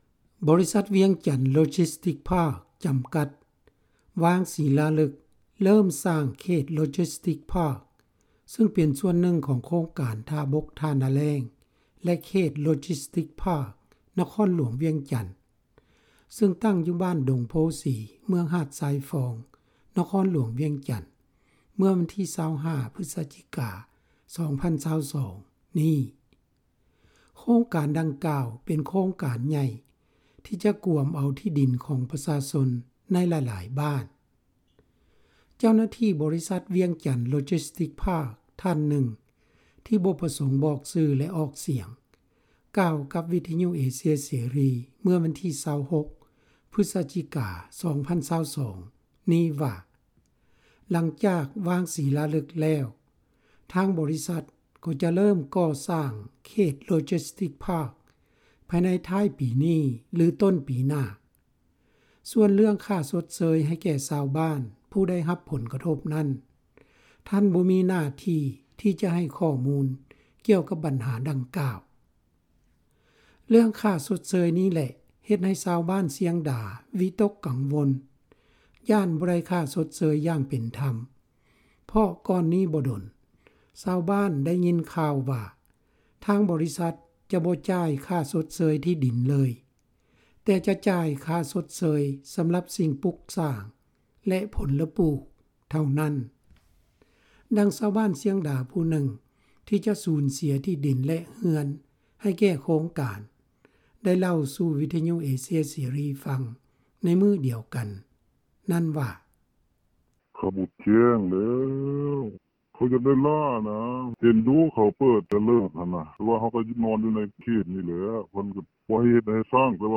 ດັ່ງຊາວບ້ານ ຊຽງດາ ຜູ້ນຶ່ງ ທີ່ຈະສູນເສັຽ ທີ່ດິນ ແລະເຮືອນໃຫ້ແກ່ໂຄງການ ໄດ້ເລົ່າສູ່ວິທະຍຸ ເອເຊັຽເສຣີ ຟັງວ່າ:
ດັ່ງ ຊາວບ້ານ ຄົນນຶ່ງເວົ້າວ່າ: